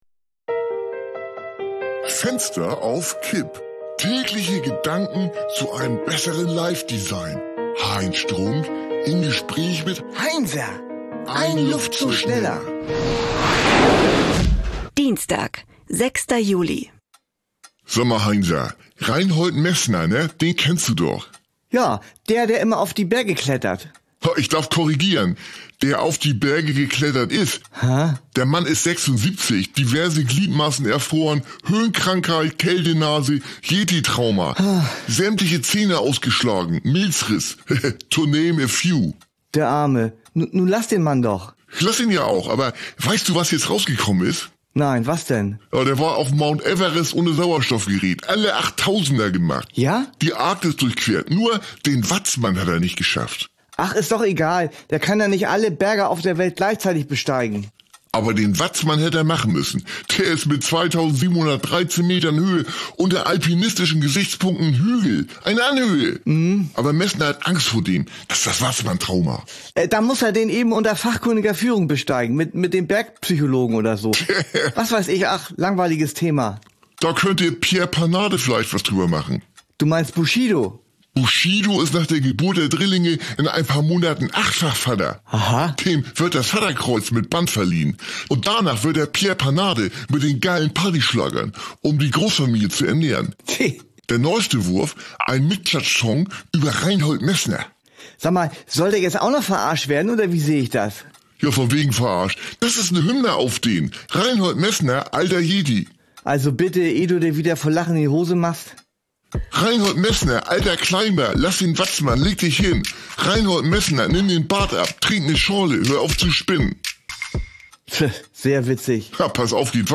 eine Audio-Sitcom von Studio Bummens
Battle-Rap zwischen Heinz und Heinzer.